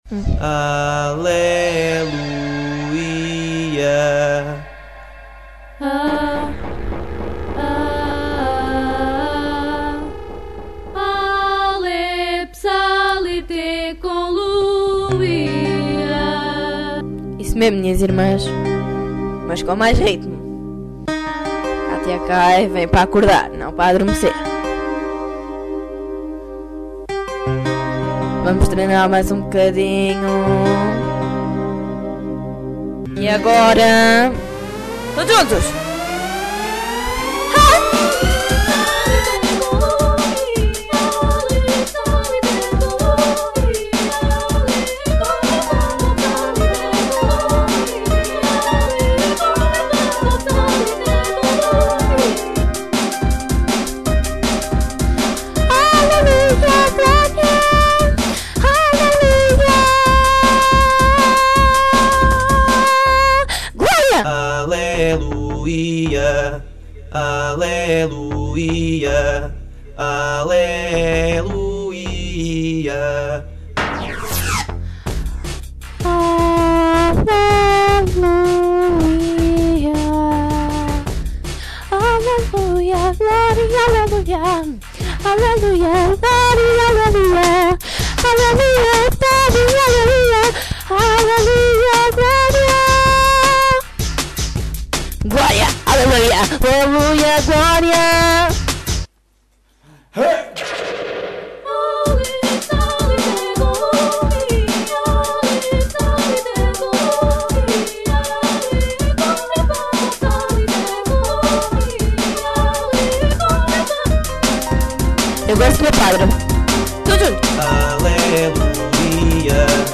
dance/electronic
A diferent mood
Drum & bass